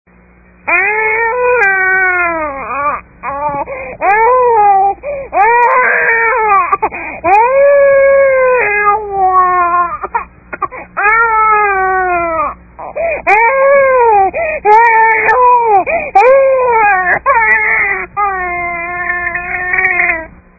Sound Effects   (Instructions: play)
Crying Baby
Crying_Baby.mp3